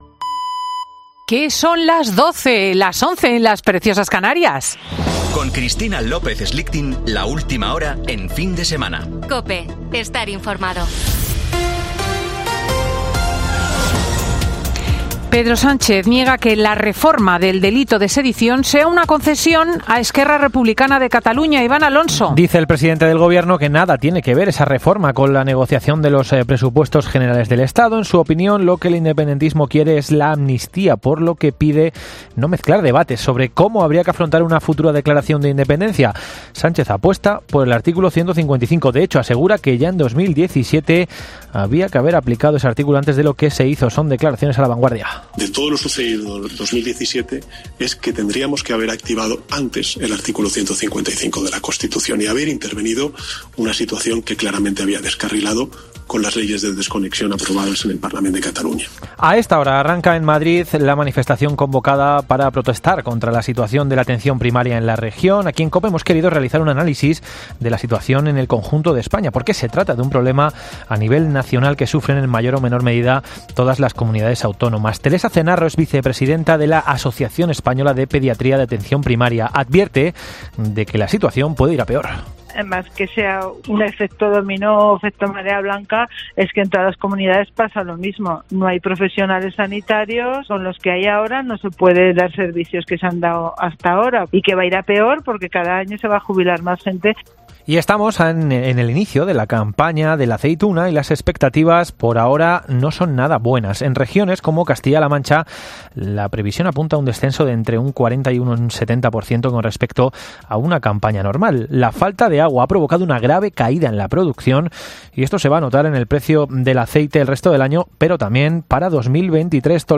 Boletín de noticias de COPE del 13 de noviembre de 2022 a las 12.00 horas